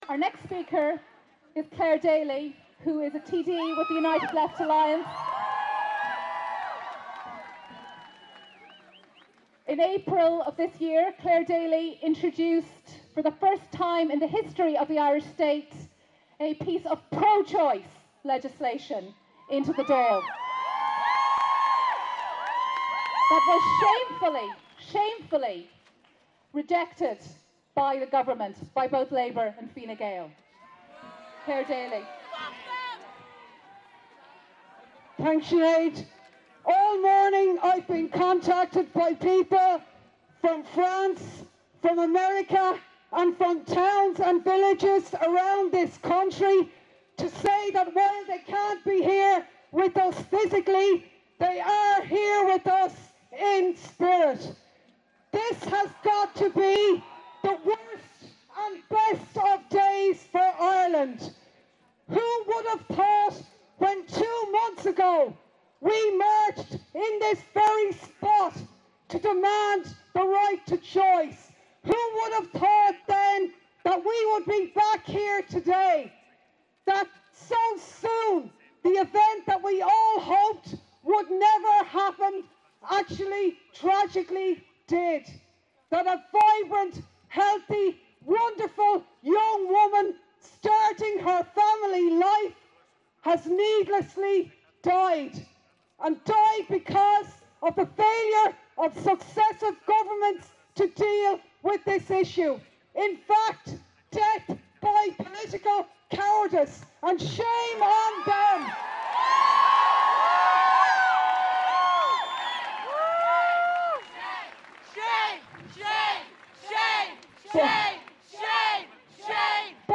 Pro Choice march in memory of Savita Halappanavar